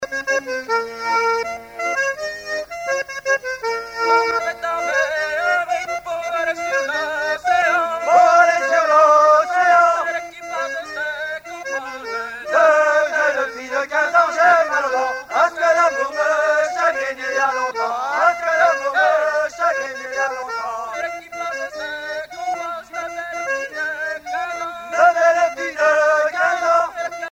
Genre laisse
Chansons de la soirée douarneniste 88
Pièce musicale inédite